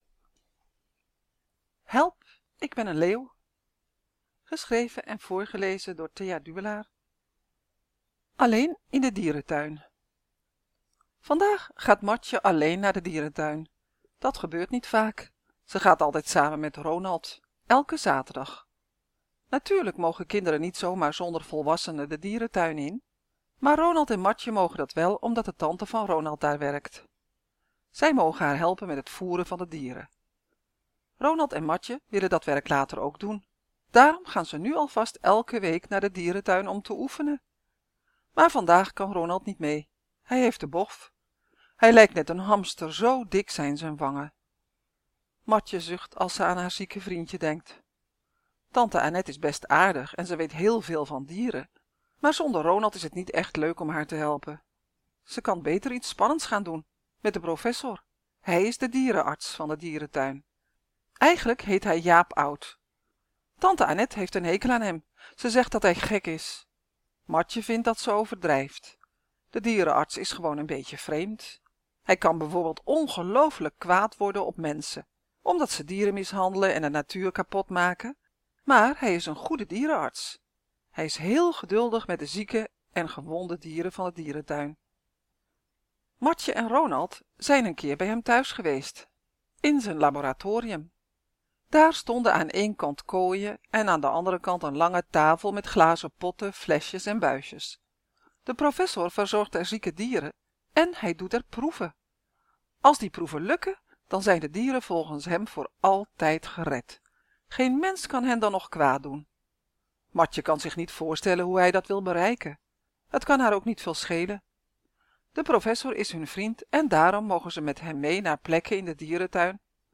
/ Verhalen / Door